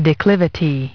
Transcription and pronunciation of the word "declivity" in British and American variants.